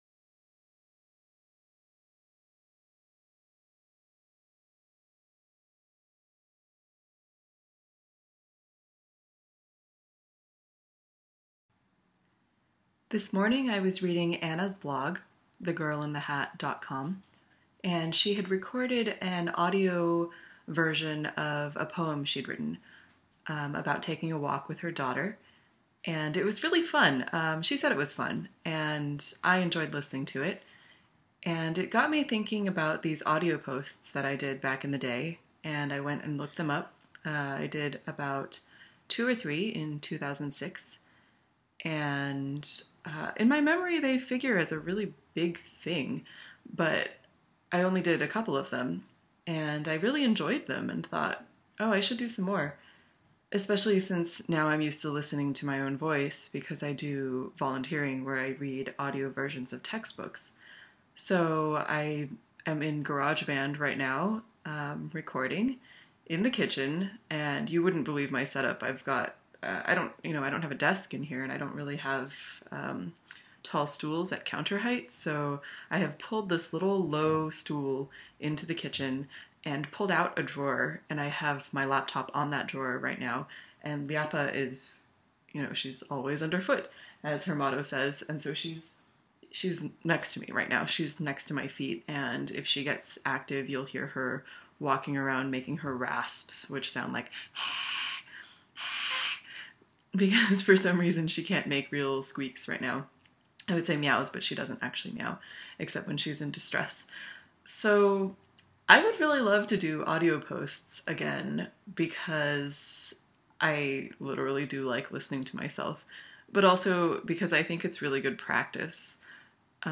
Voice post
Me, unscripted.
Sorry, audio starts at about 0:12. I haven’t tried to figure out editing yet.